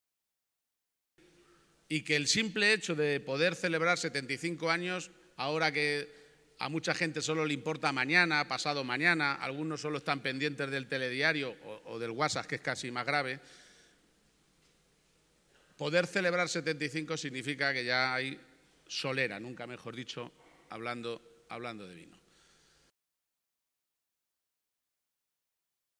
En una breve intervención ante los socios y socias congregadas en este aniversario de la cooperativa constituida en 1950, el presidente regional ha destacado que este “75 aniversario pone de manifiesto que hay muchas cosas de las que poder presumir” y que “significa que hay solera”, ha concluido.